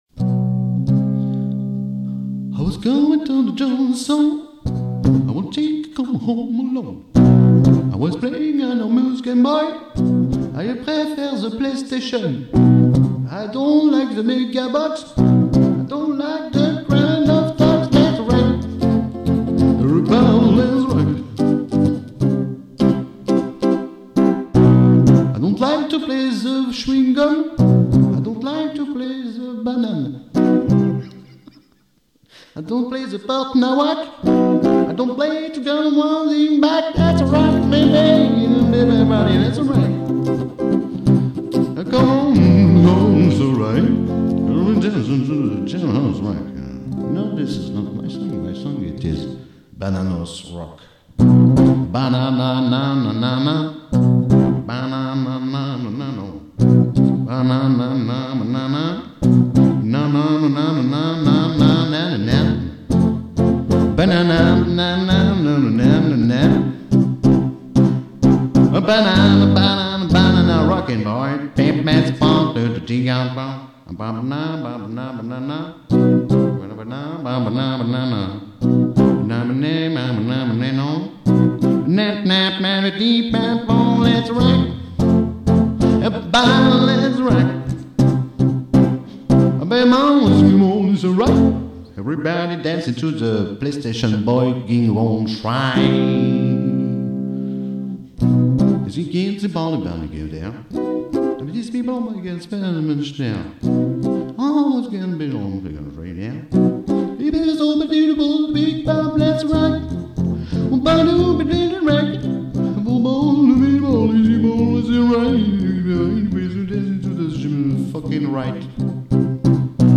Rock classique déjanté. 2005